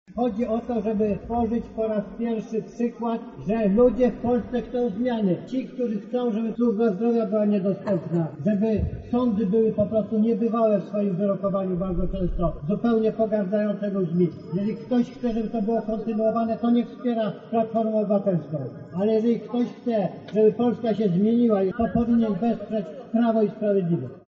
Jeśli ludzie w Polsce chcą zmiany, to powinni wesprzeć Prawo i Sprawiedliwość – to słowa Jarosława Kaczyńskiego, wypowiedziane dzisiaj podczas spotkania na Placu Litewskim w Lublinie.